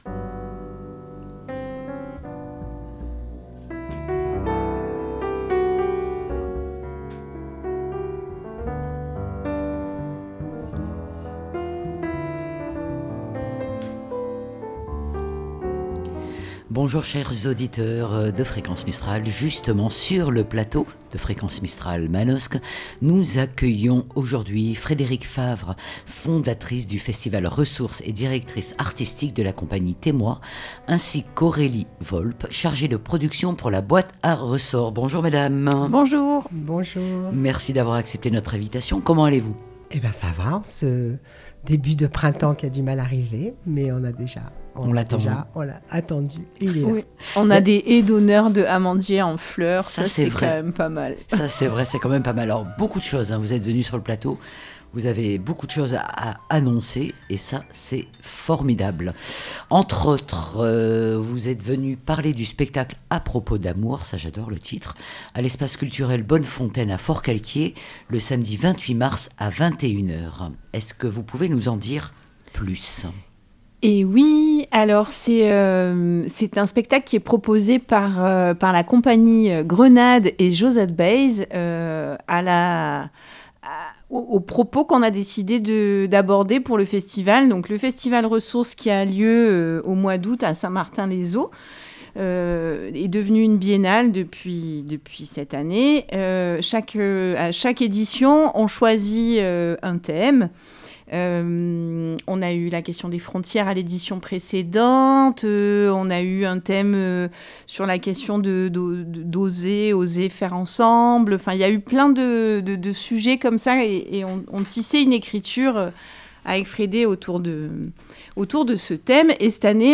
Sur le plateau de Fréquence Mistral